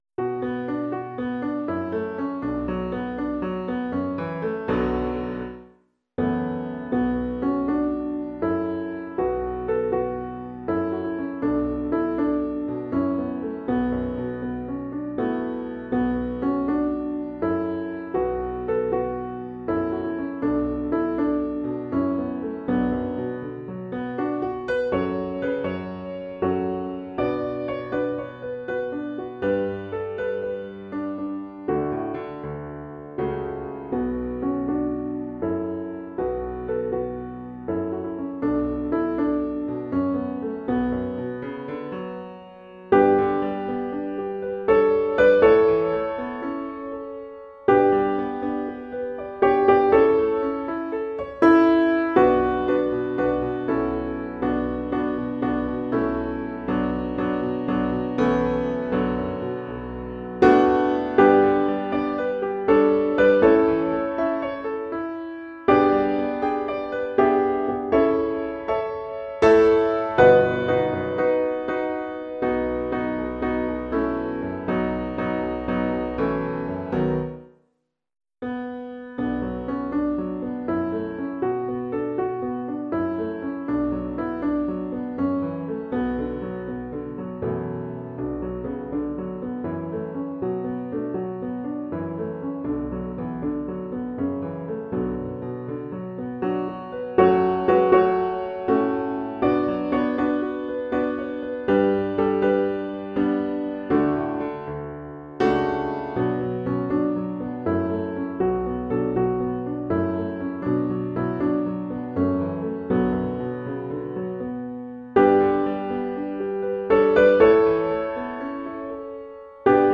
Practice Recordings